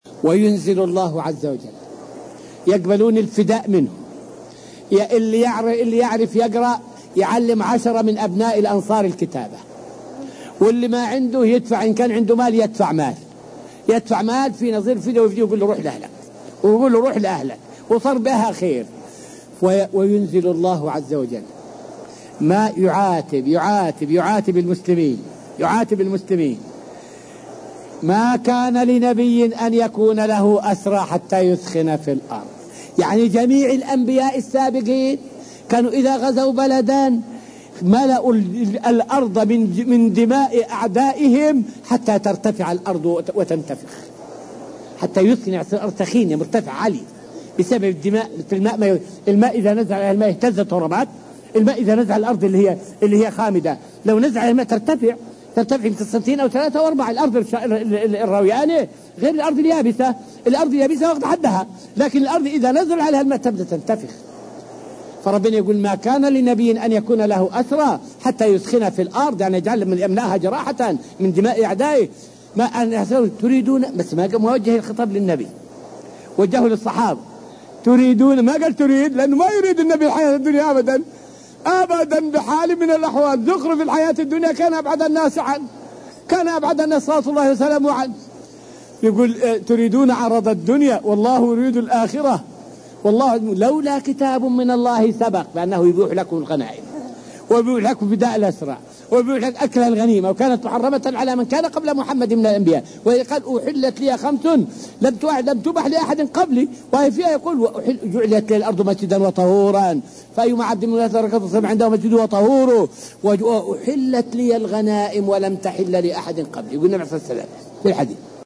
فائدة من الدرس الثامن عشر من دروس تفسير سورة البقرة والتي ألقيت في المسجد النبوي الشريف حول معنى قوله تعالى {ما كان لنبي أن يكون له أسرى}.